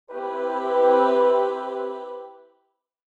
level-up-transition-gfx-sounds-1-00-03.mp3